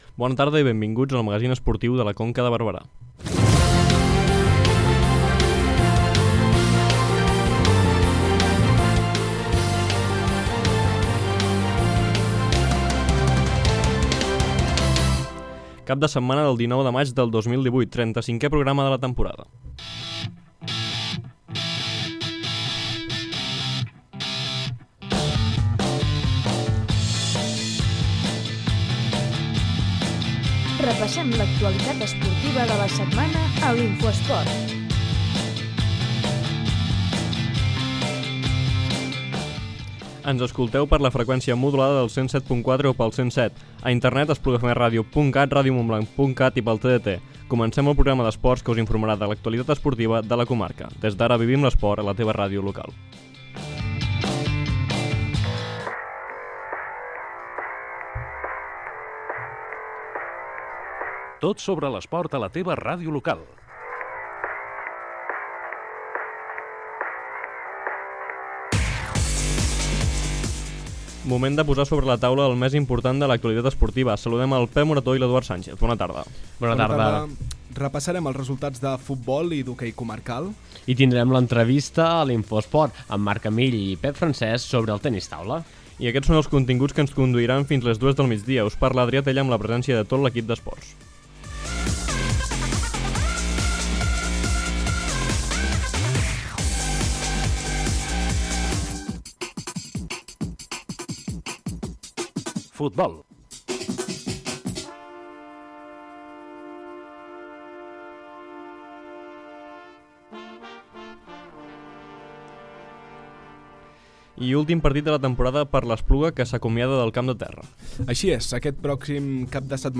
hem entrevistat